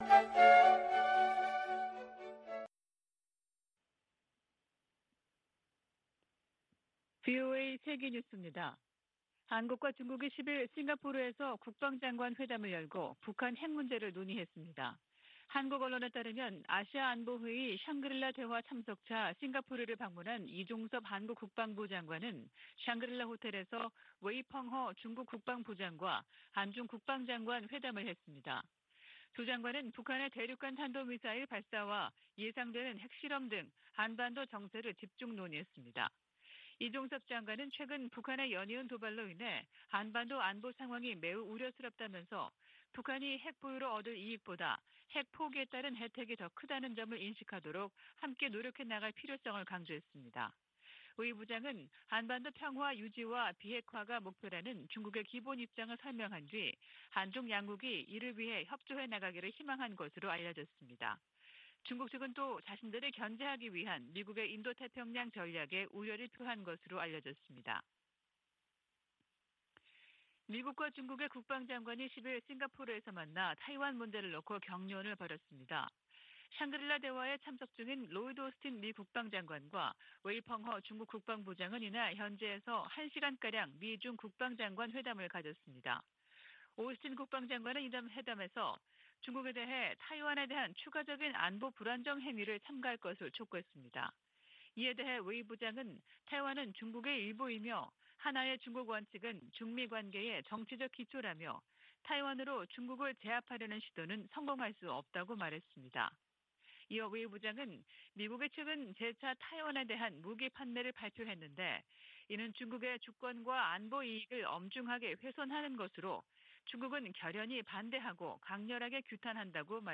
VOA 한국어 아침 뉴스 프로그램 '워싱턴 뉴스 광장' 2022년 6월 11일 방송입니다. 윤석열 한국 대통령이 한국 정상으로는 처음 나토 정상회의에 참석합니다. 북한이 핵실험을 강행하면 억지력 강화, 정보유입 확대 등 체감할수 있는 대응을 해야 한다고 전직 미국 관리들이 촉구했습니다. 유엔 주재 중국대사가 북한의 추가 핵실험을 원하지 않는다는 입장을 밝히면서도, 실험을 강행할 경우 중국의 반응을 추측해서도 안된다고 말했습니다.